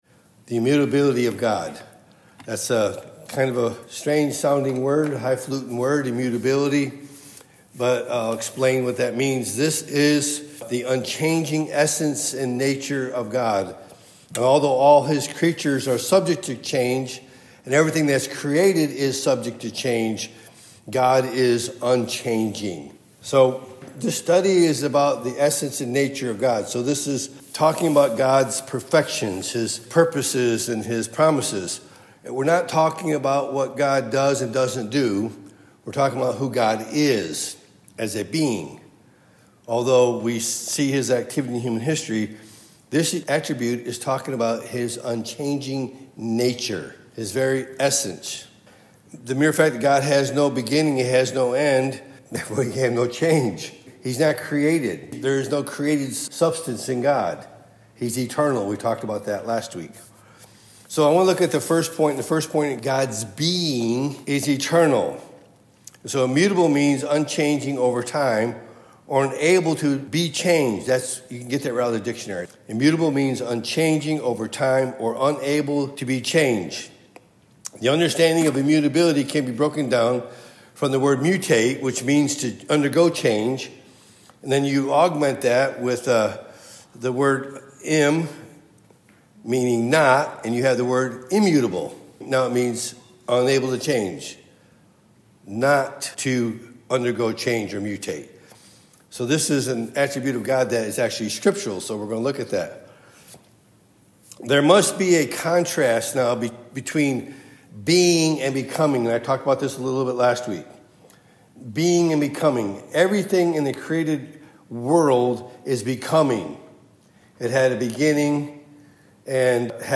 Topic: Wednesday Pastoral Bible Study